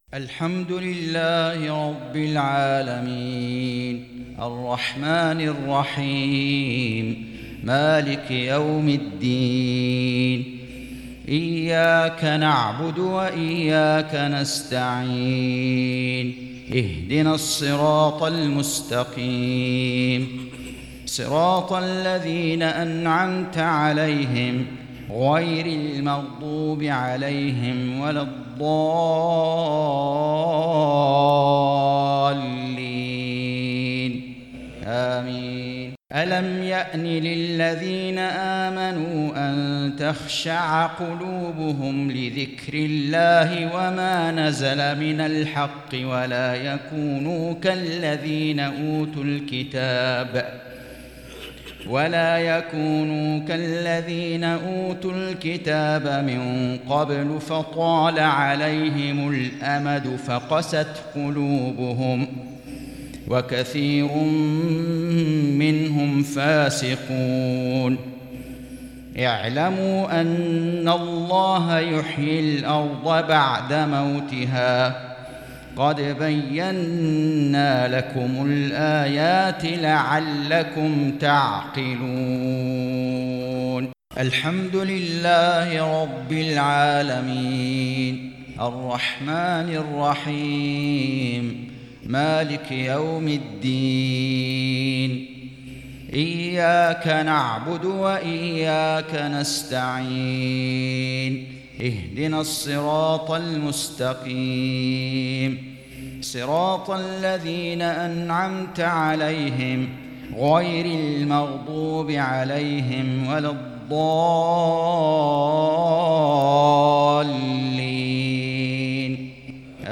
صلاة المغرب الشيخان فيصل غزاوي وعـبـد الباري الثبيتي قرأ الشيخ فيصل غزاوي ســورتي الهمزة والماعون بينما قرأ الشيخ عـبـد الباري الثبيتي ســورتي القدر والهمزة